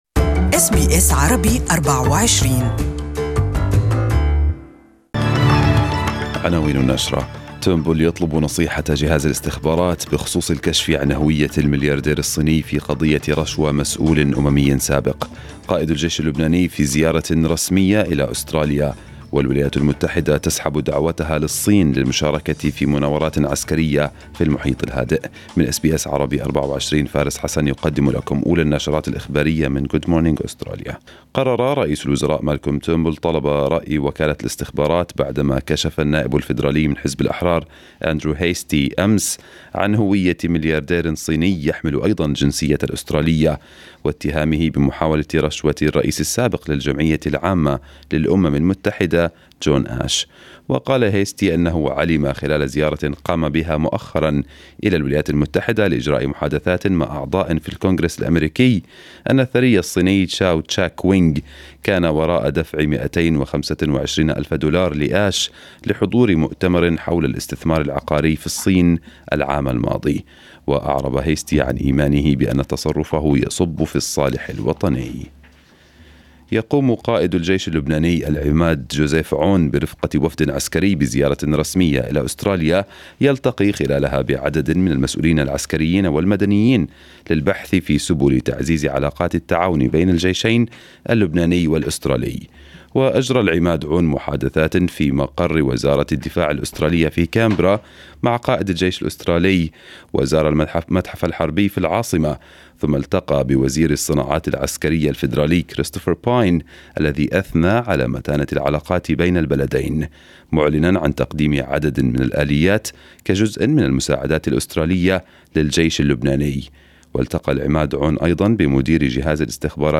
Arabic News Bulletin 24/05/2018